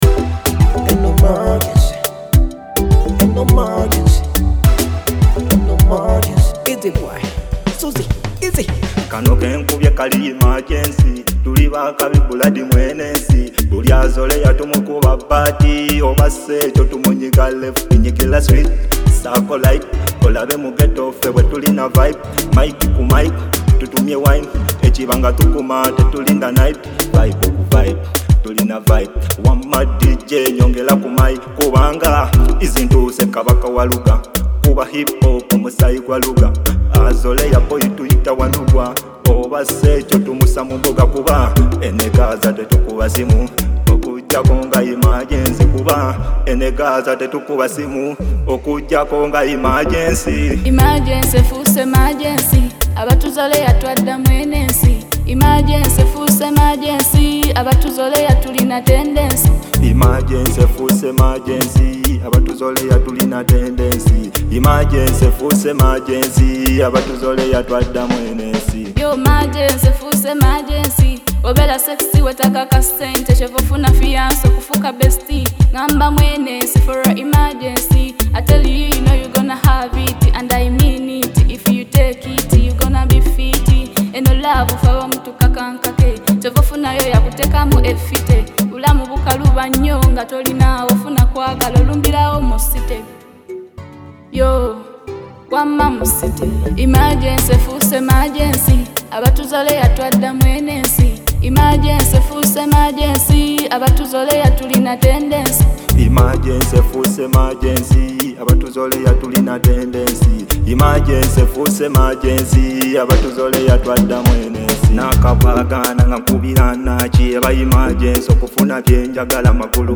Genre: Luga Flow